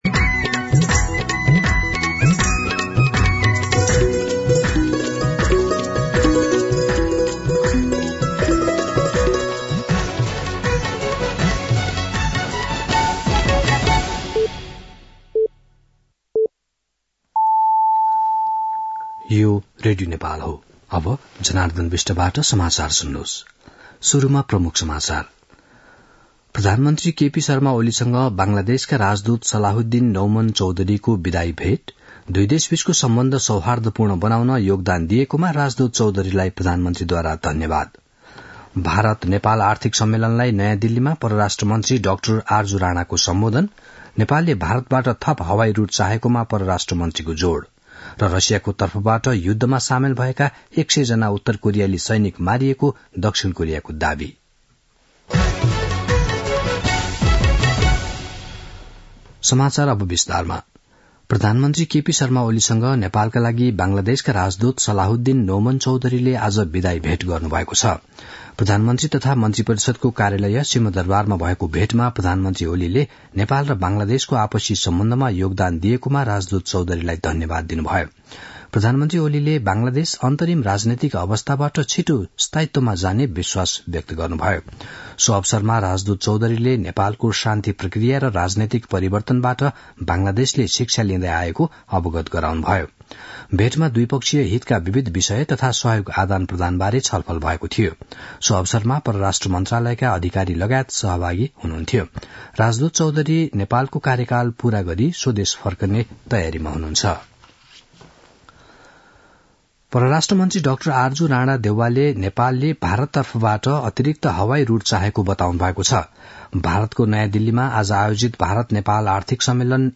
दिउँसो ३ बजेको नेपाली समाचार : ६ पुष , २०८१
3-pm-nepali-news-1.mp3